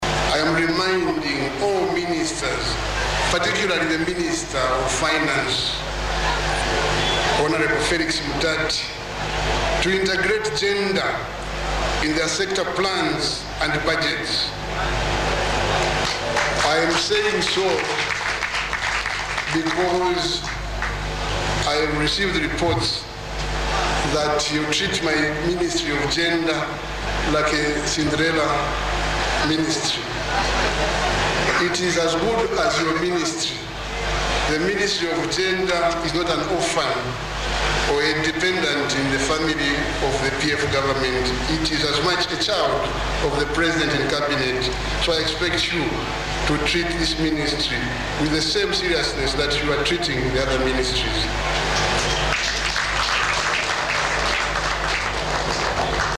Speaking at the 2017 commemoration of the International Women’s Day under the theme “Women in the Changing World of Work Planet 50-50 by 2030 yesterday at Lusaka’s Agriculture Show Grounds, President urged Finance Minister Felix Mutati, who is opposition Movement for Multiparty Democracy (MMD) faction leader, to study the PF manifesto to help him deliver in his portfolio.
PRESIDENT-LUNGU-ON-FINANCE-MINISTER-1.mp3